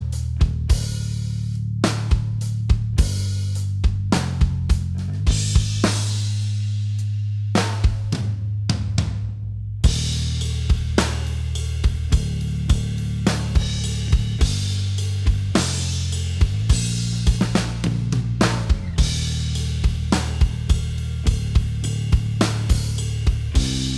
Minus All Guitars Rock 5:22 Buy £1.50